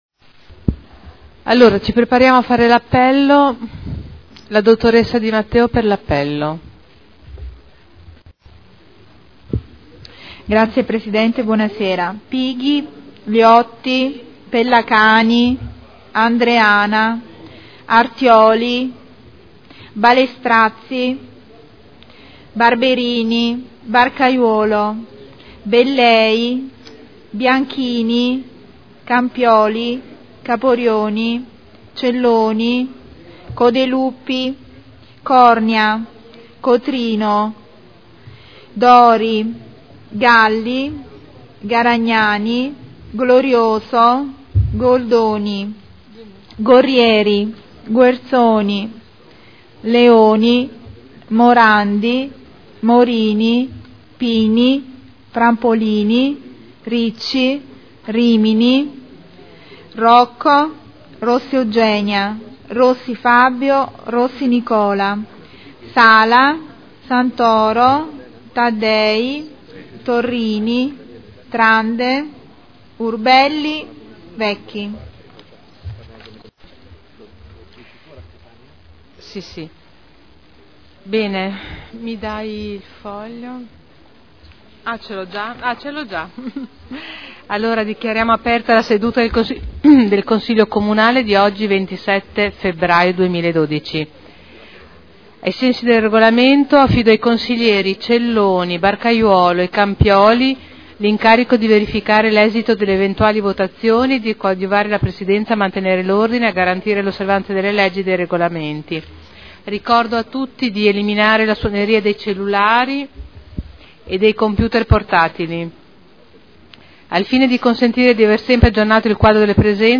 Presidente — Sito Audio Consiglio Comunale
Apertura del Consiglio Comunale. Appello